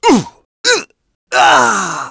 Clause-Vox_Dead.wav